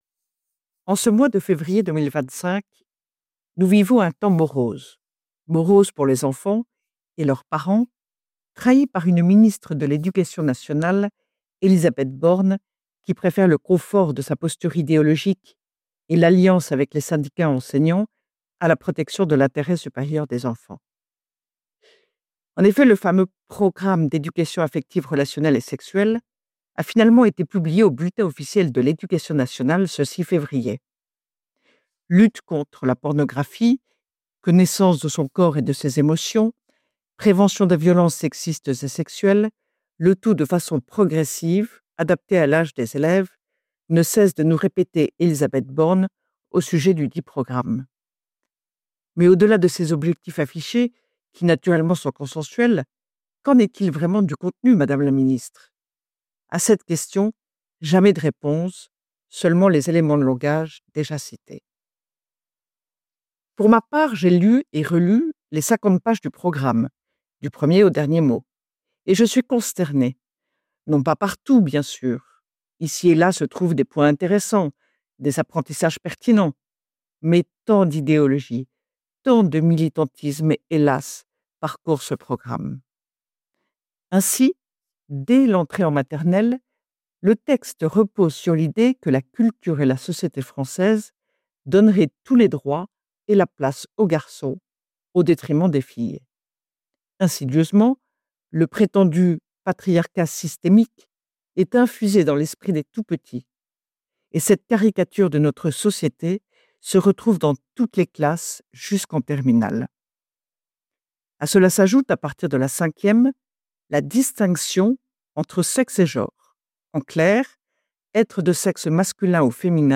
diffusée le samedi sur Radio Espérance